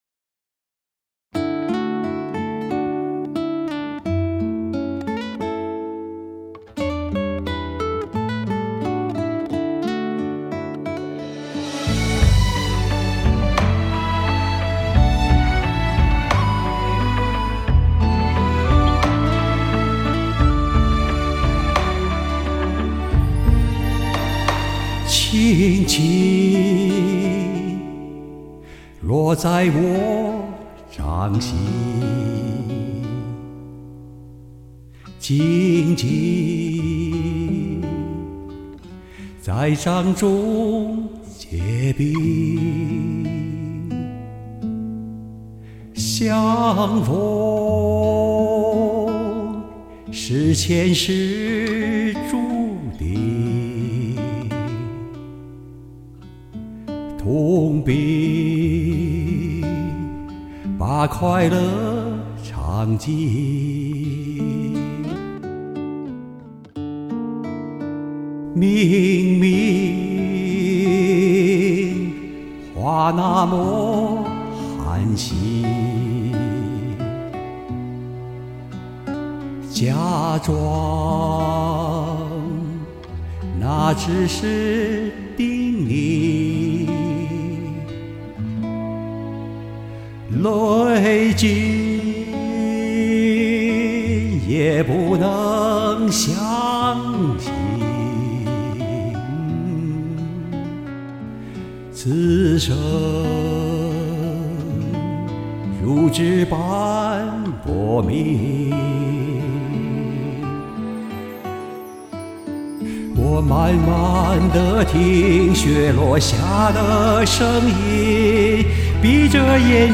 颤音不是有意而为，只是下意识，不易控制。。。
好歌好唱！磁性温暖深情！